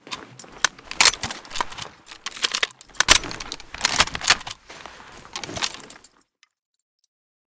pkm_unjam.ogg